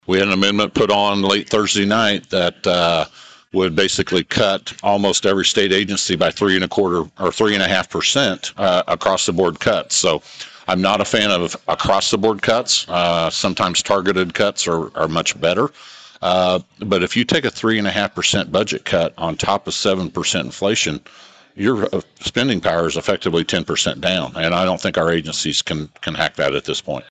With the end of the 2023 regular legislative session approaching, local lawmakers were able to offer updates on a variety of topics during the latest legislative dialogue at the Flint Hills Technical College main campus Saturday morning.